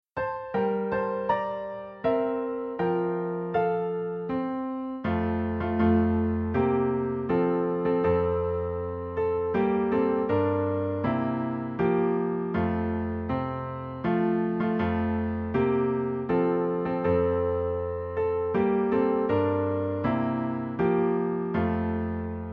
Sheet Music — Piano Solo Download
Piano Solo
Downloadable Instrumental Track